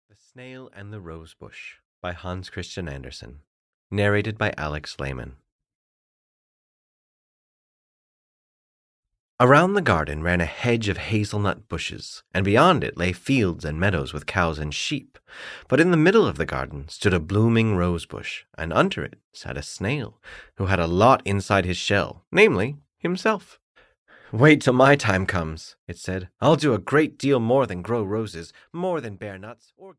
The Snail and the Rosebush (EN) audiokniha
Ukázka z knihy